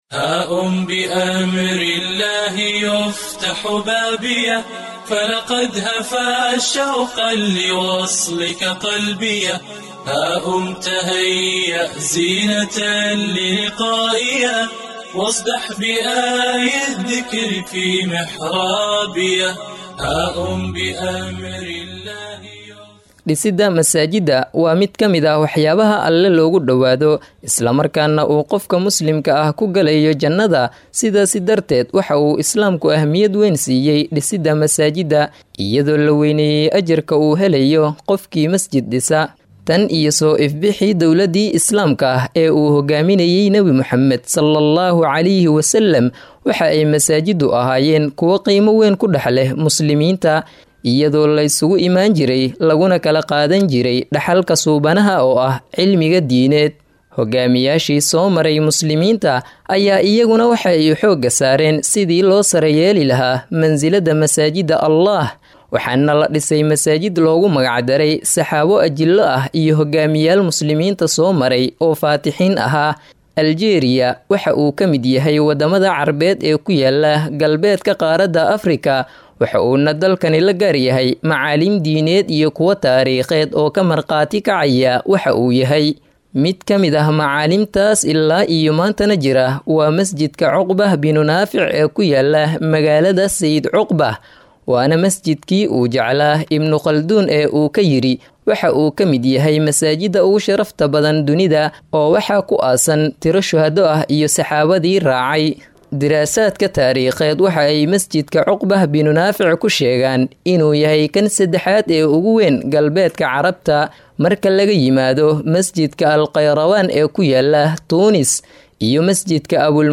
Warbixin-Asbaabaha-Arzaaqda.mp3